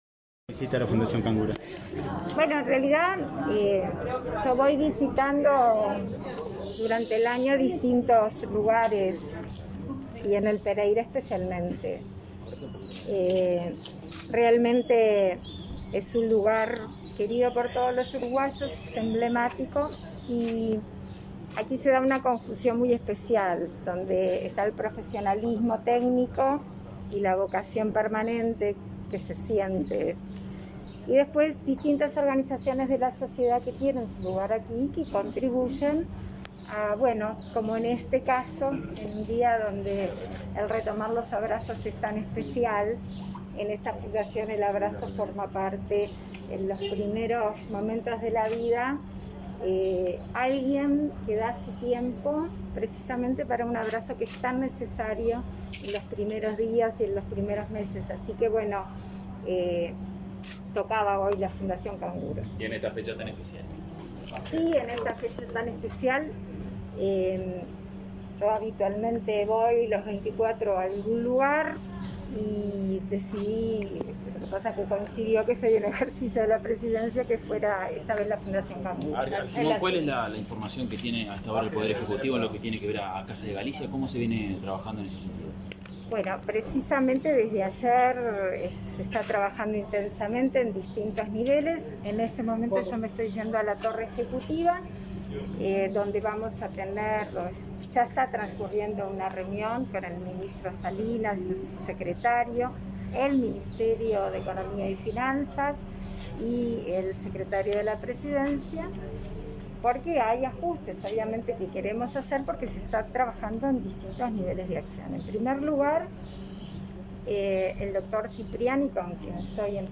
Declaraciones de la presidenta en ejercicio, Beatriz Argimón, a la prensa
La presidenta en ejercicio, Beatriz Argimón, dialogó con la prensa, luego de visitar la fundación Canguro, en el hospital Pereira Rossell.